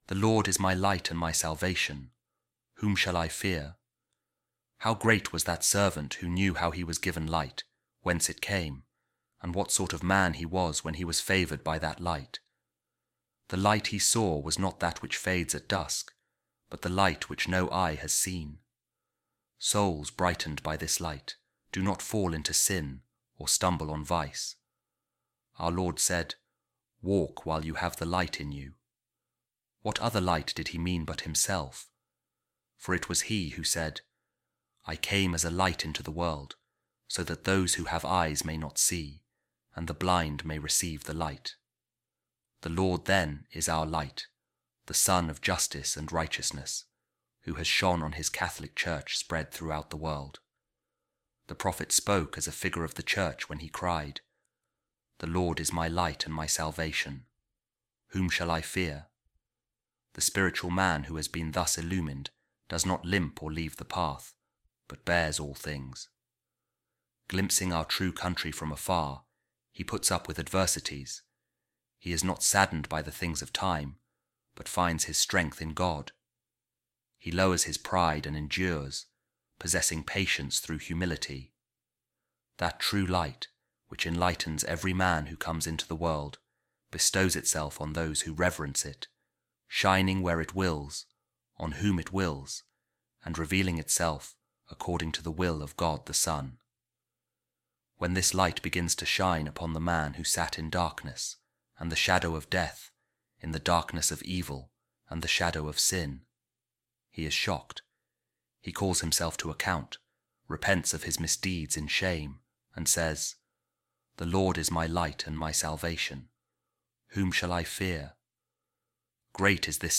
A Reading From The Sermons Of Bishop John Of Naples | Love The Lord And Walk In His Ways